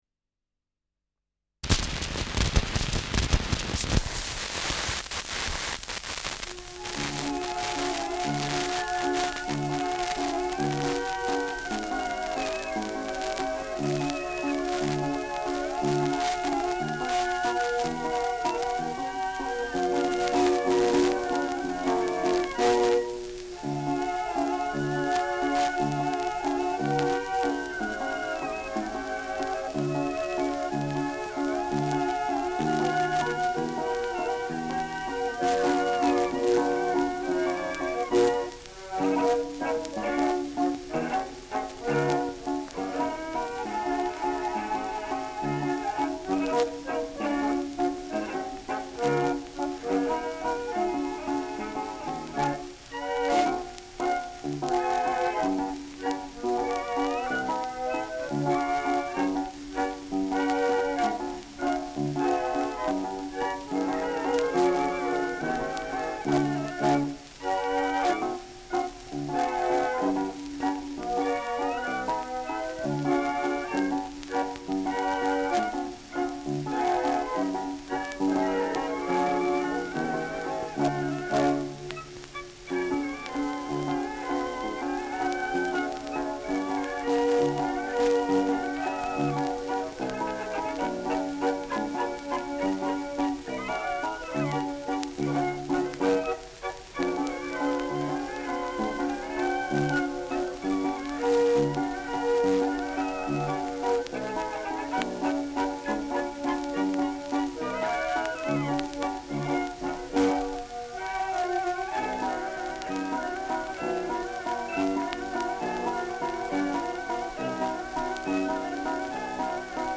Schellackplatte
ausgeprägtes Rauschen
Original Lanner-Quartett (Interpretation)